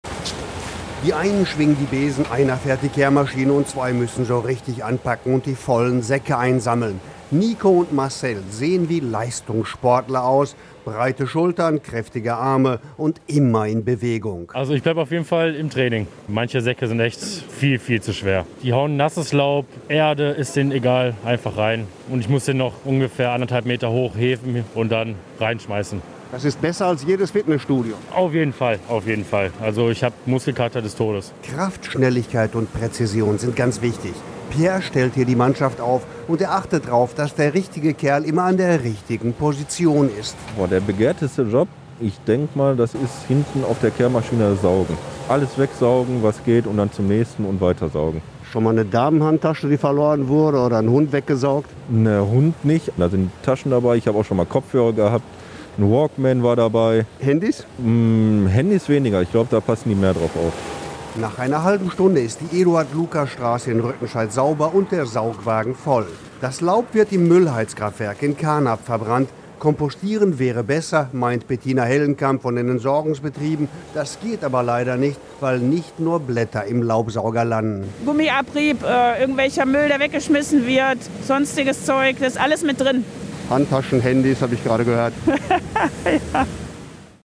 EBE-Truppe beim Laubeinsatz in Rüttenscheid begleitet